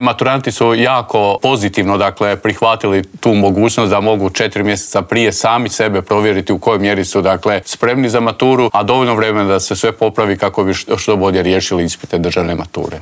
ZAGREB - U Intervjuu tjedna Media servisa razgovarali smo s ravnateljem Nacionalnog centra za vanjsko vrednovanje obrazovanja, Vinkom Filipovićem.